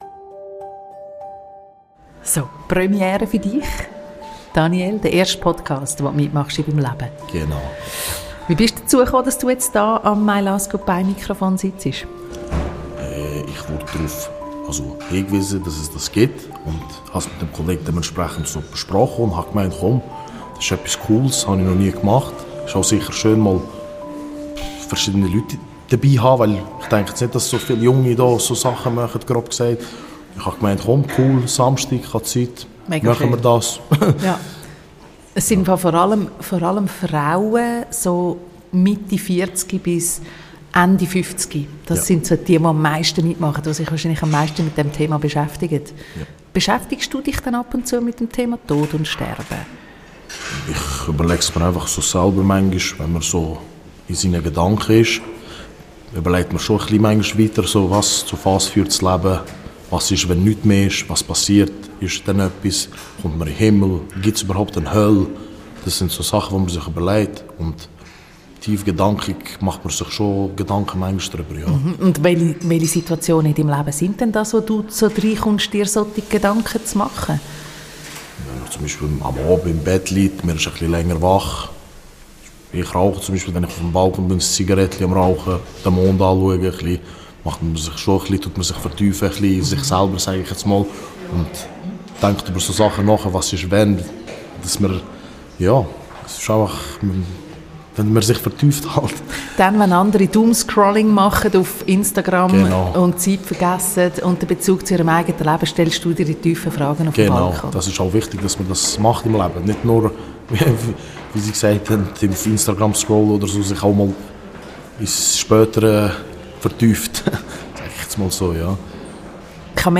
Beschreibung vor 1 Jahr Willkommen zur "Bescht Kolleg Edition", Part II Entstanden ist diese Folge im Rahmen der Ausstellung "Lebenshalt" zum 30-jährigen Jubiläum von Hospiz Aargau.